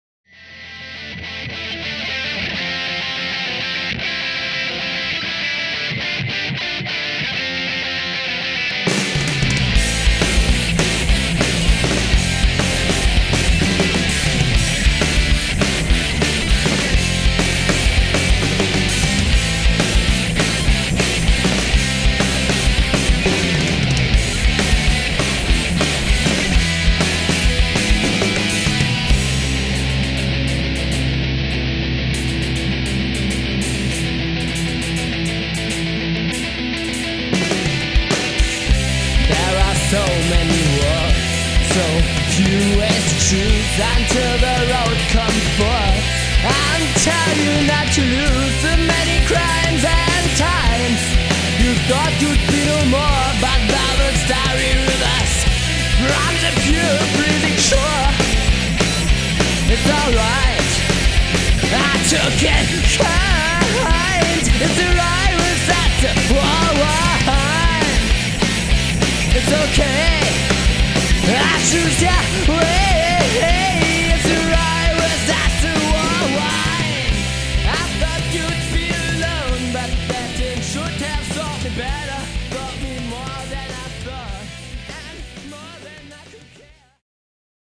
Seit 2003 sind wir dabei für euch abzurocken!
Gesang, Gitarre
Bass
Schlagzeug